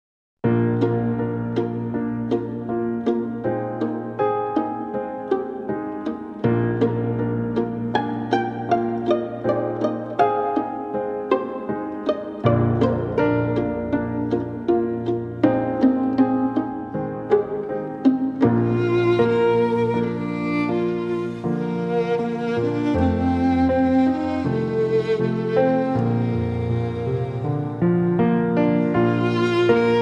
Instrumental
Violinistin